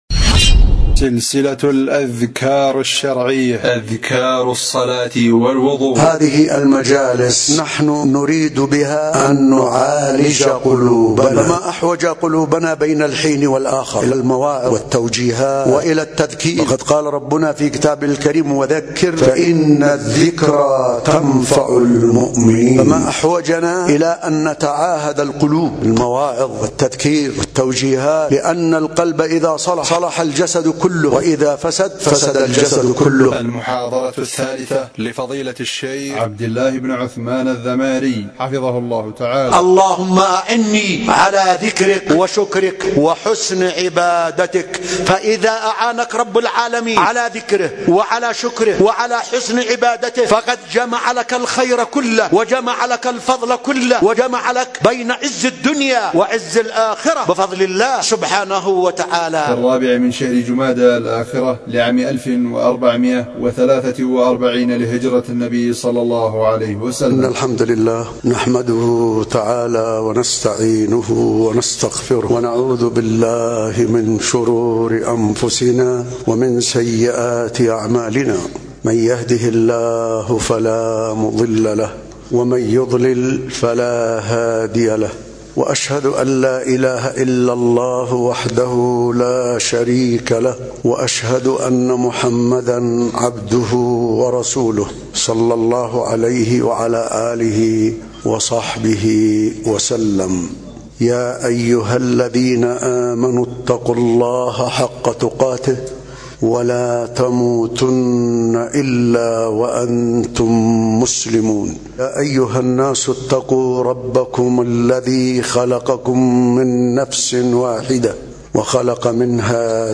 محاضرة قيّمة